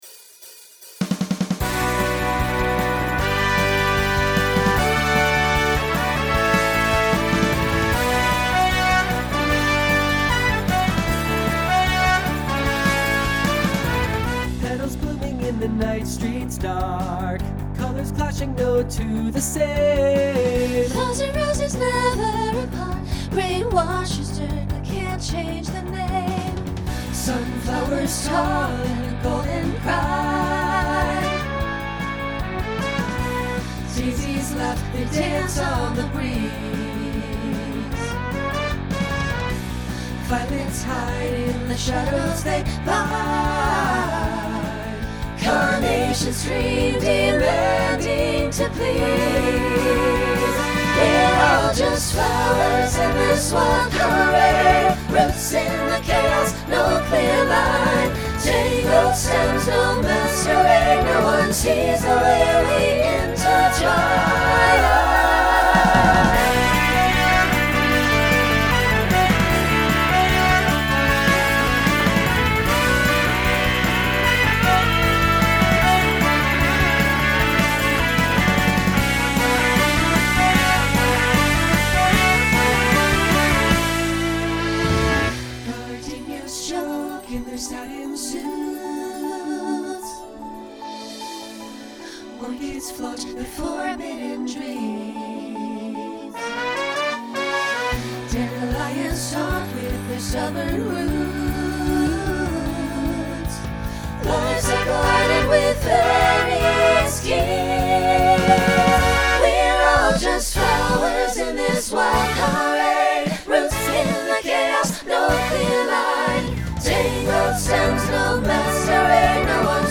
Original AI-generated song, no licensing necessary.